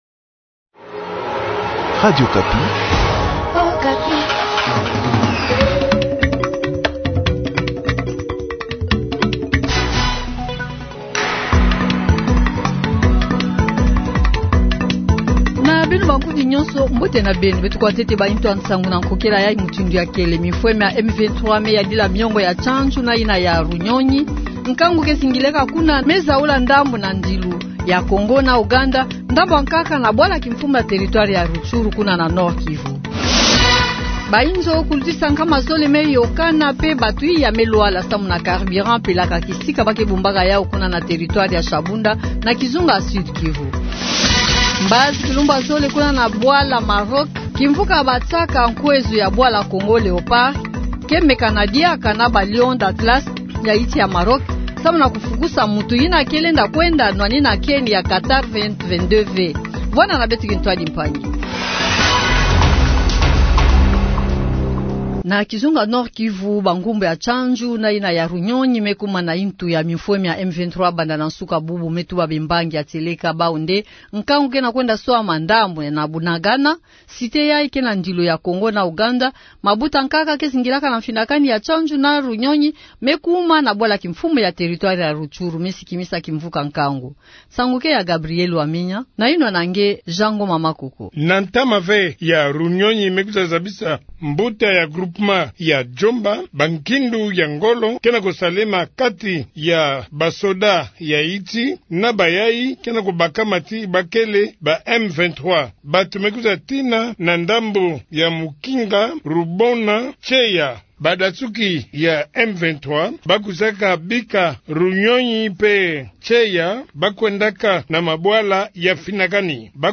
Journal Du Soir